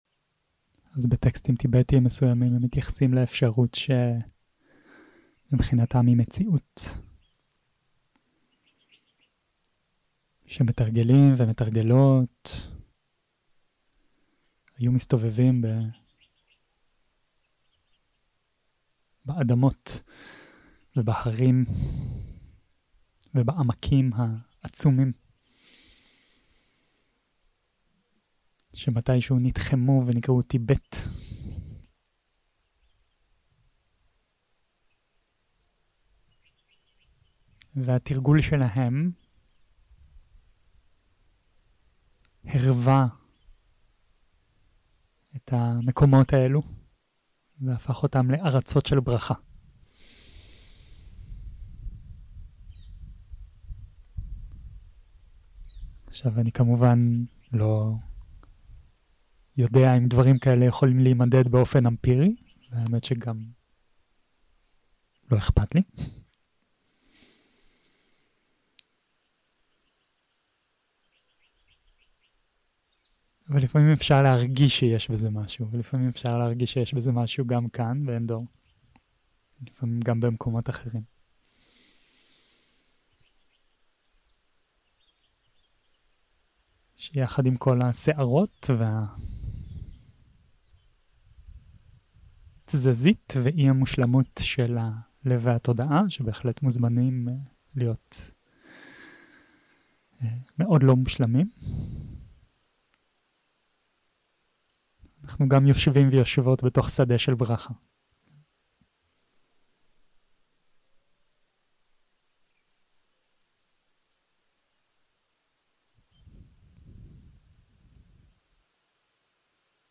שיחות דהרמה